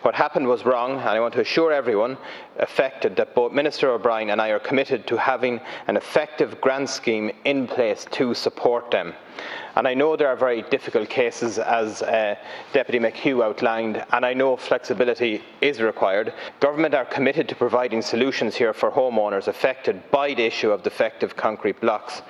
In response, Minister of State with Responsibility for Local Government and Plannig, Peter Burke admitted flexibility is needed: